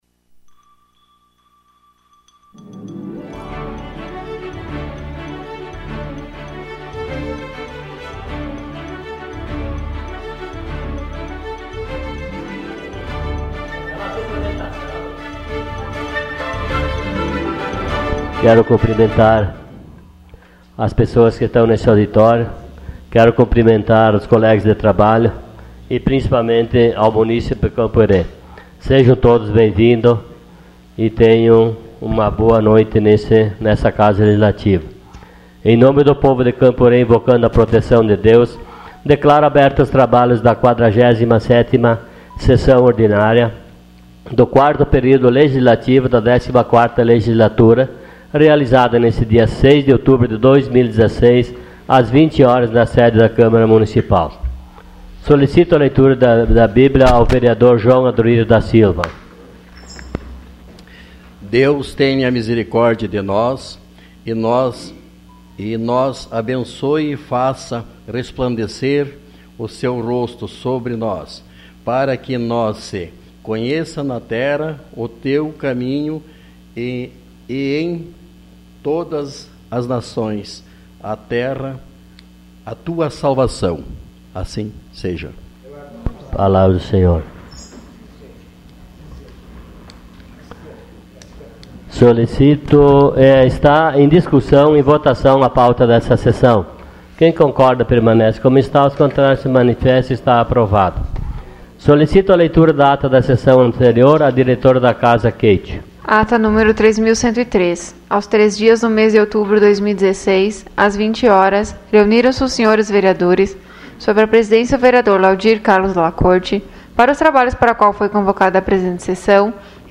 Sessão Ordinária dia 06 de outubro de 2016.